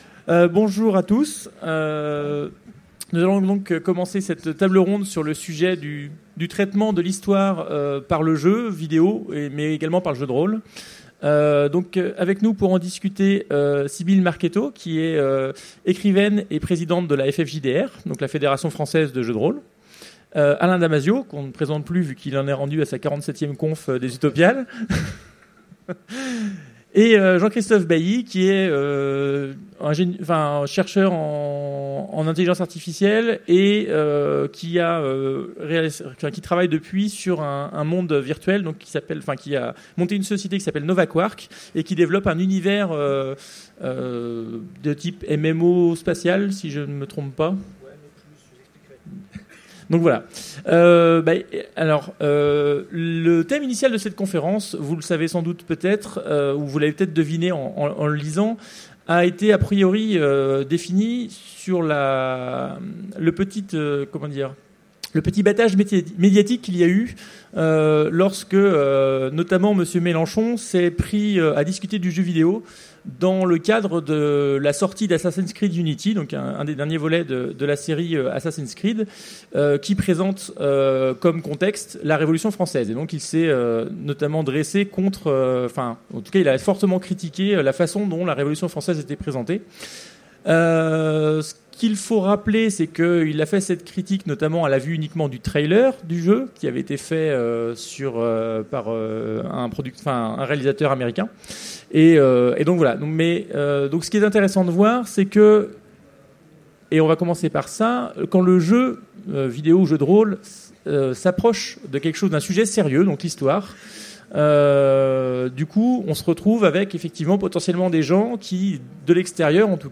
Utopiales 2015 : Conférence L’Histoire dans les jeux vidéo